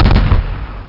EXPLO2.mp3